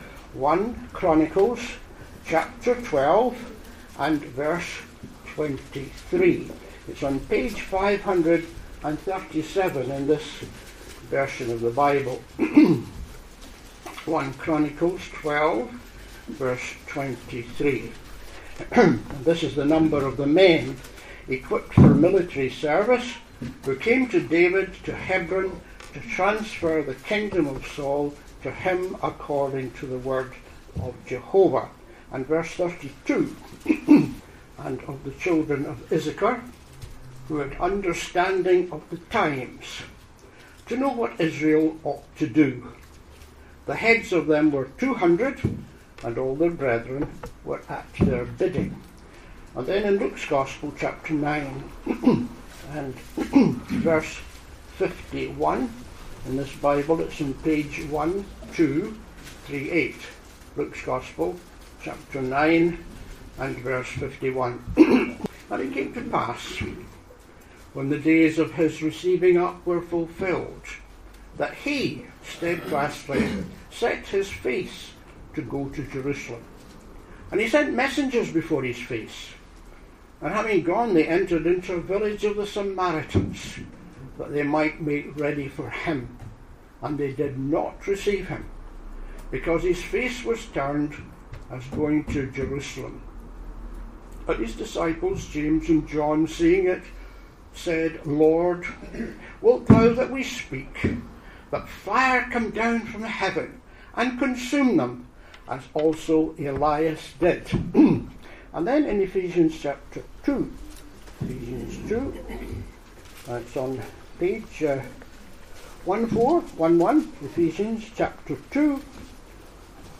Christians need to understand the times in which we live that we might know how best to serve the Lord Jesus. Listen to the following address, to discover what the scriptures say about understanding the times.